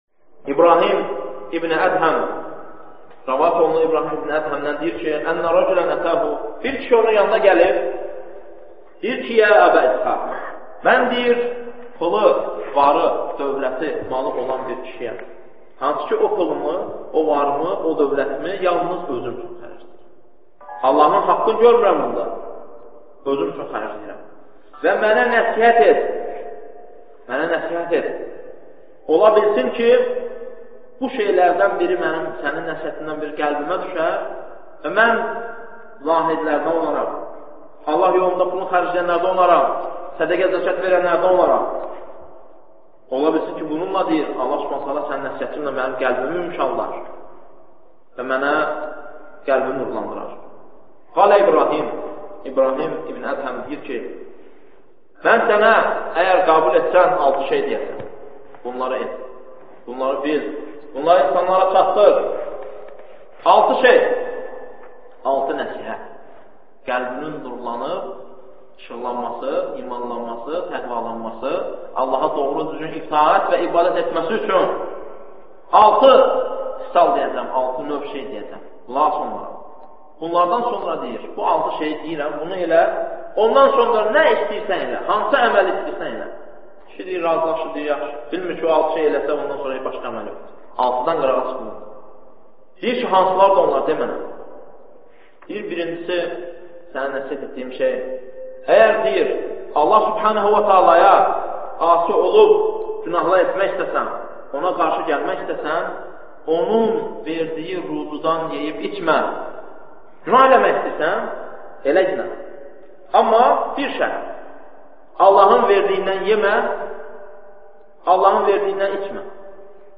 Dərslərdən alıntılar – 100 parça